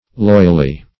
Loyally \Loy"al*ly\, adv.